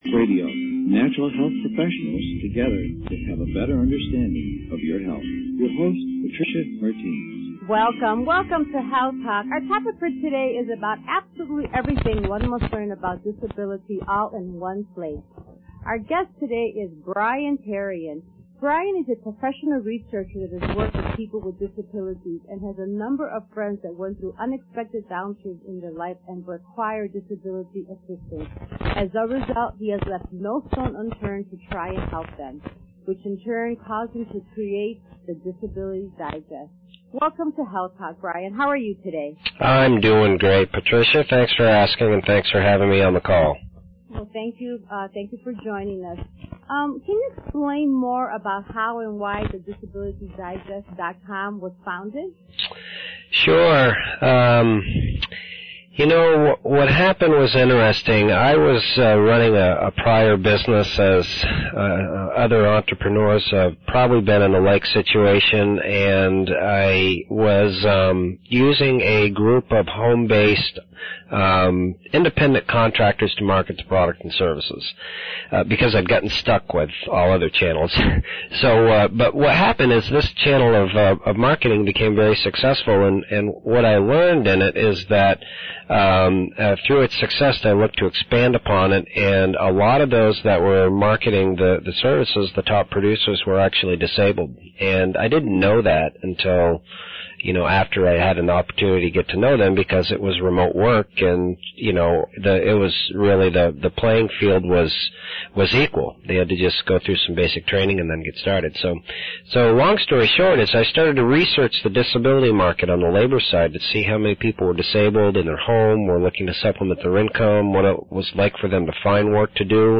A FREE Interview that explains resource for people (on or in need of) disability benefits!
healthtalkradio.mp3